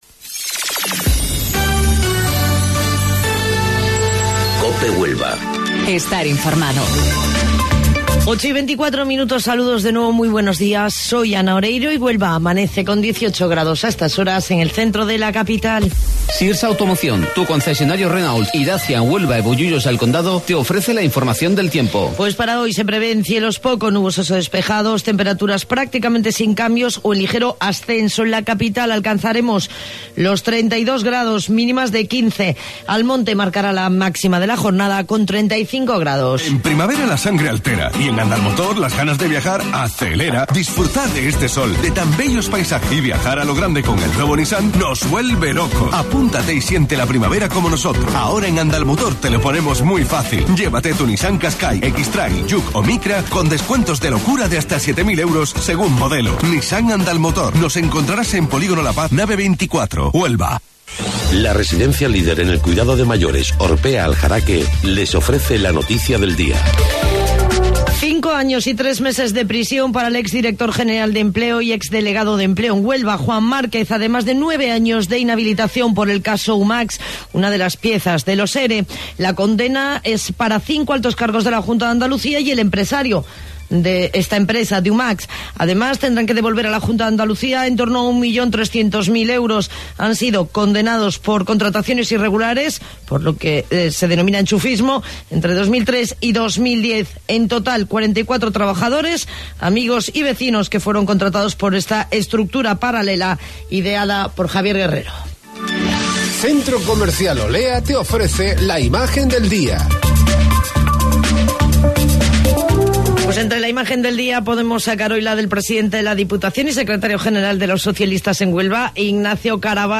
AUDIO: Informativo Local 08:25 del 31 de Mayo